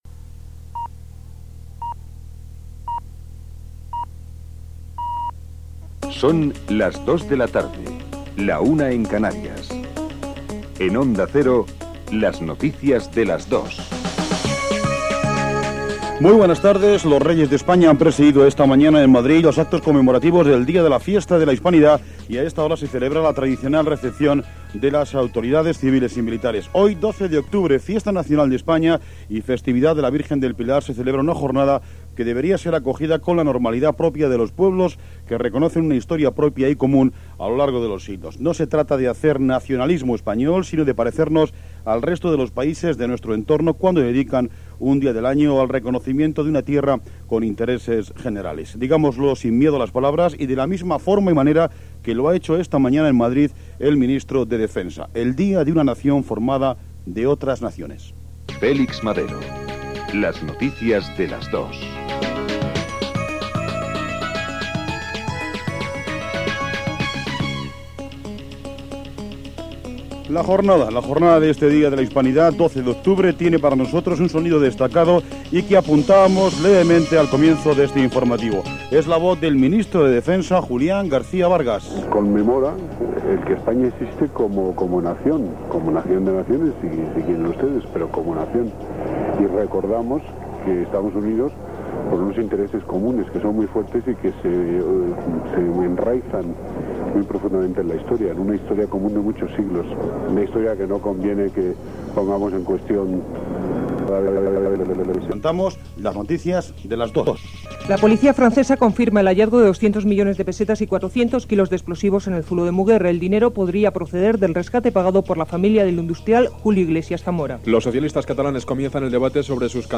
Hora, indicatiu del programa, Festa Nacional espanyola del 12 d'octubre, indicatiu, declaracions del ministre espanyol Vargas, sumari informatiu
Informatiu